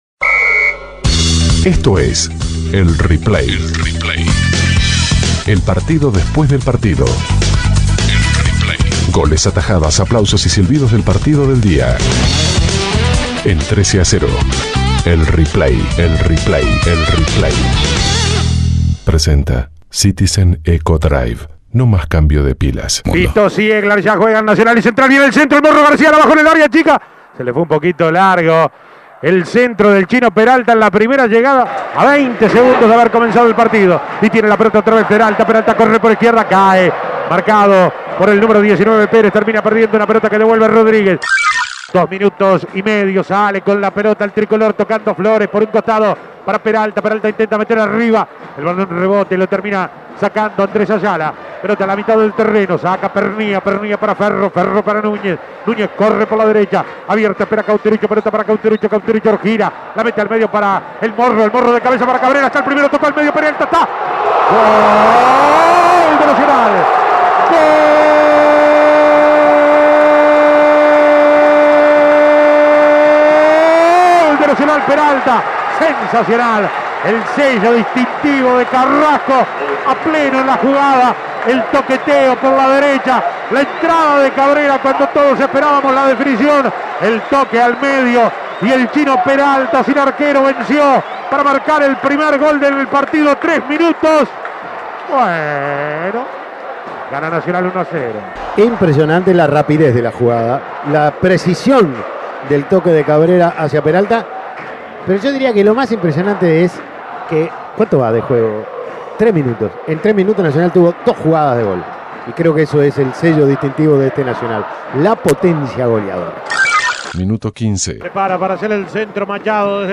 Goles y comentarios Escuche el replay de Central - Nacional Imprimir A- A A+ Nacional venció a Central Español por 3 goles a 1.